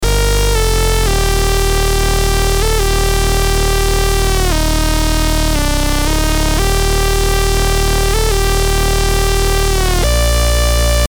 Bass Saw 5 Gmin
Antidote_Zodiac-Bass-Saw-5-Gmin.mp3